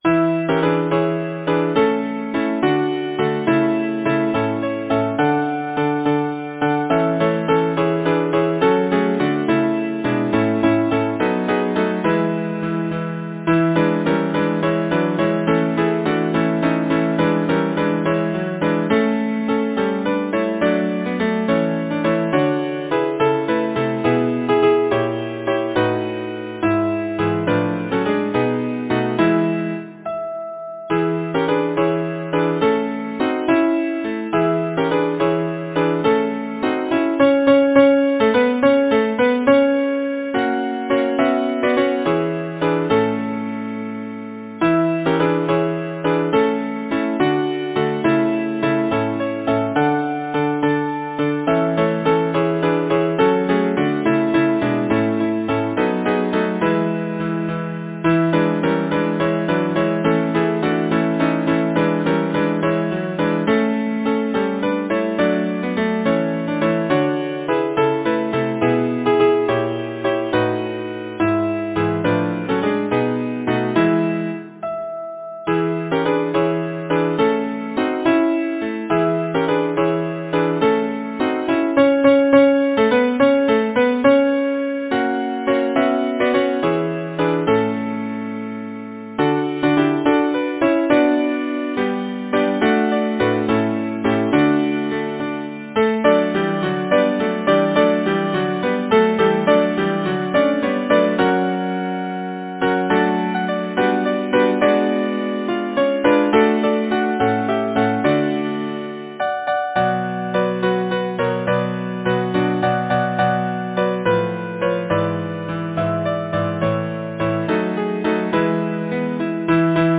Title: Under the Maple Tree Composer: Henry Baumer Lyricist: Isidore Ascher Number of voices: 4vv Voicing: SATB Genre: Secular, Partsong
Language: English Instruments: A cappella